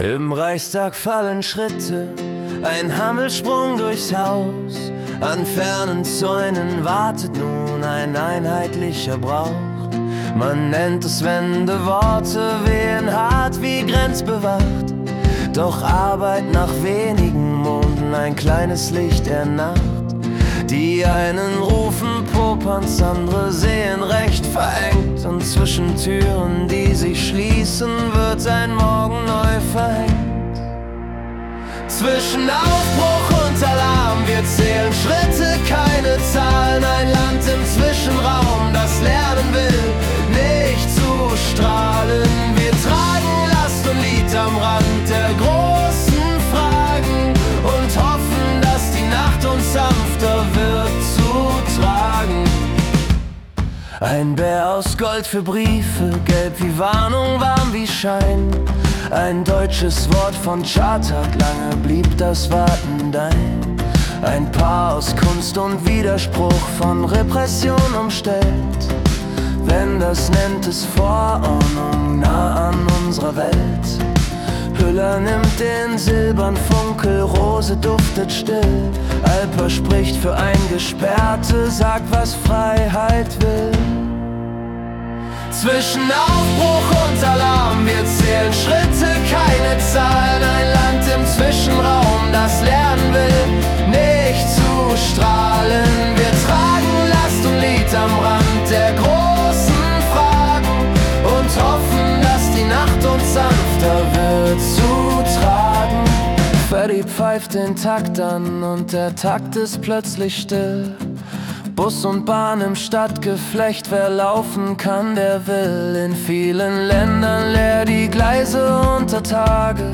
März 2026 als Singer-Songwriter-Song interpretiert.